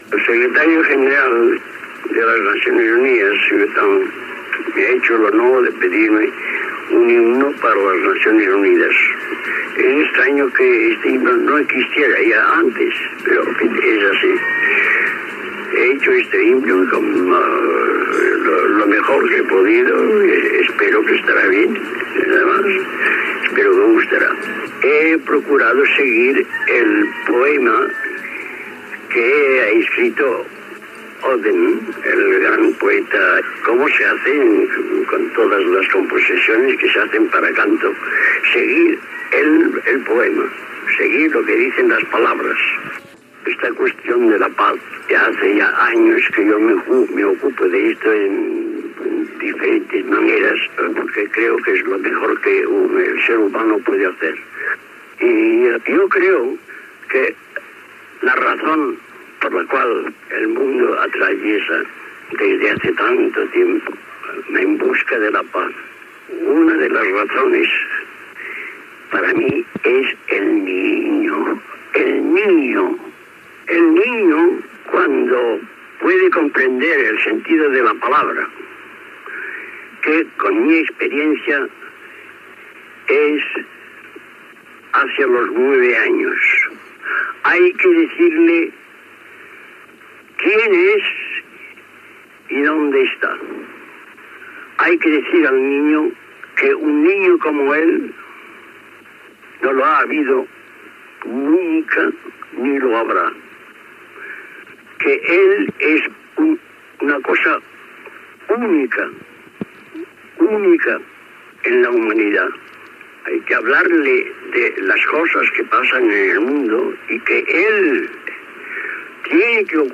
El músic Pau Casals explica que les Nacions Unides li ha demanat que composi un himne per a la institució i reflexiona sobre la recerca de la pau al món